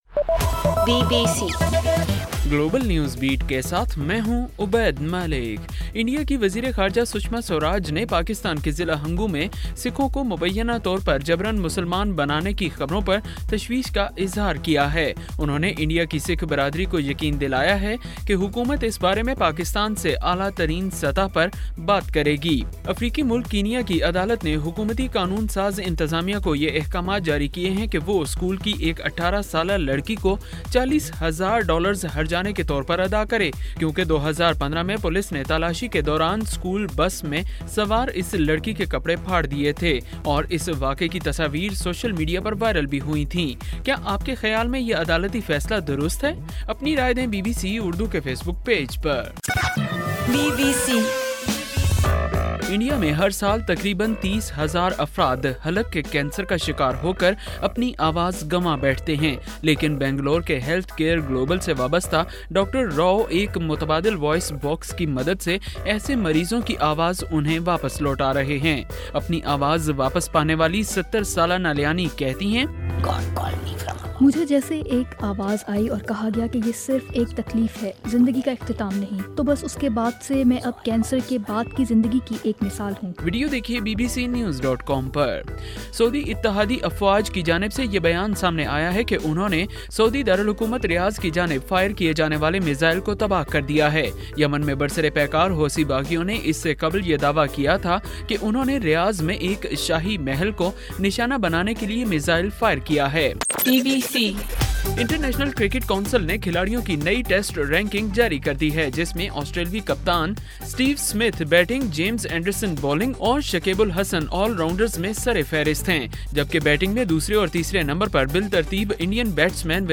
گلوبل نیوز بیٹ بُلیٹن اُردو زبان میں رات 8 بجے سے صبح 1 بجے تک ہر گھنٹےکے بعد اپنا اور آواز ایفایم ریڈیو سٹیشن کے علاوہ ٹوئٹر، فیس بُک اور آڈیو بوم پر ضرور سنیے